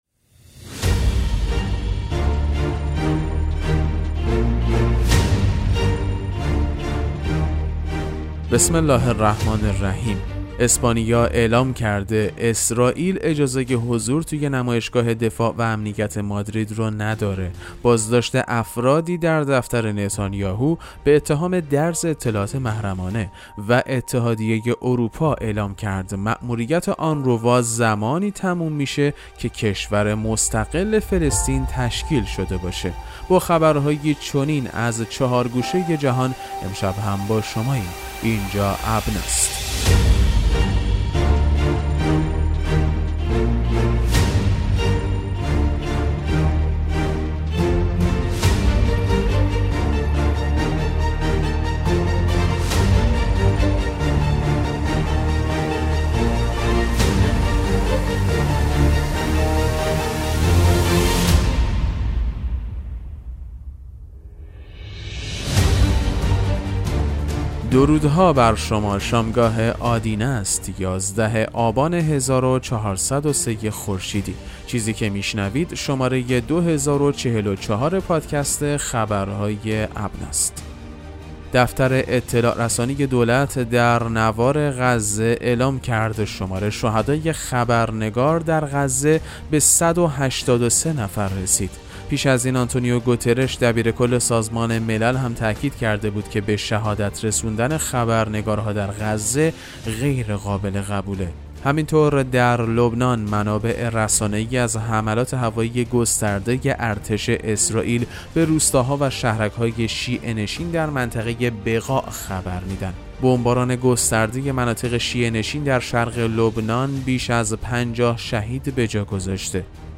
پادکست مهم‌ترین اخبار ابنا فارسی ــ 11 آبان 1403